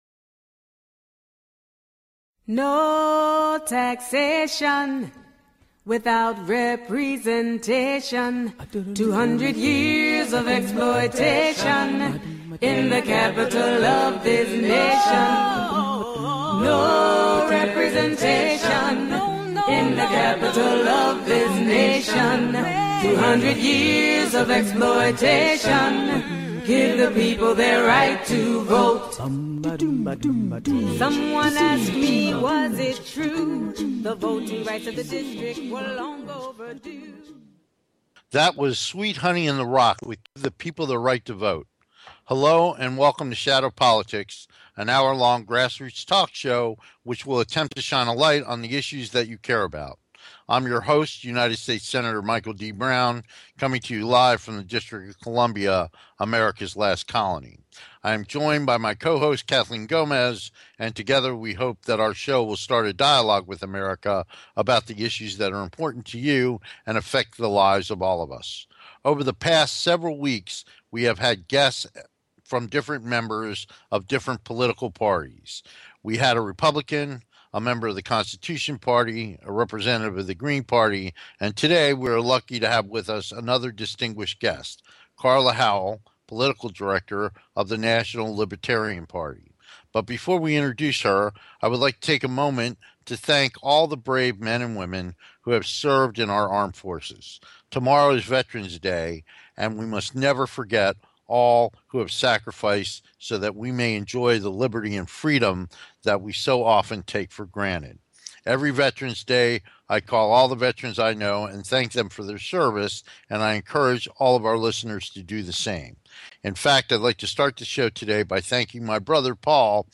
The show is not only informative but intertaining, and they both convey their opinions with humor, wit and a strong rapport.
Shadow Politics is a grass roots talk show giving a voice to the voiceless.
We look forward to having you be part of the discussion so call in and join the conversation.